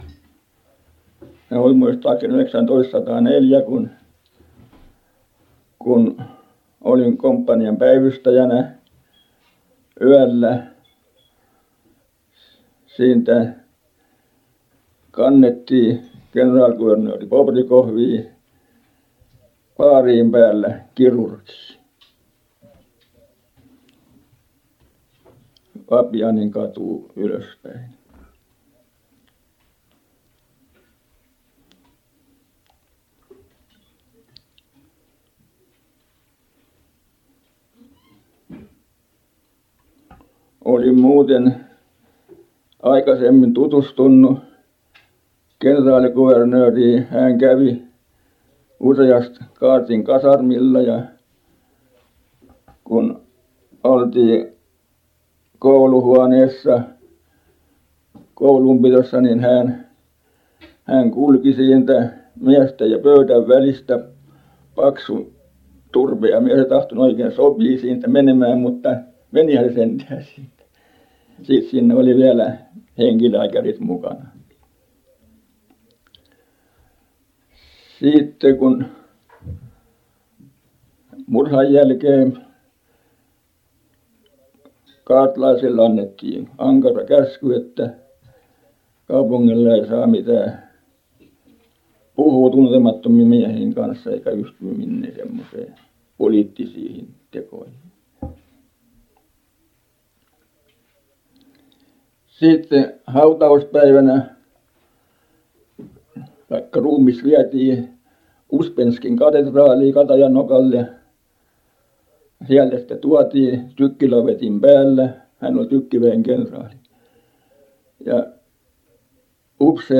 Inspelningarna innehåller huvudsakligen fritt tal av så kallade informanter, och berättelser om ämnen som de känner till, minnen och erfarenheter.
Inspelningarna gjordes från och med slutet av 1950-talet under intervjuresor med arkivets personal och insamlare som fått finansiering.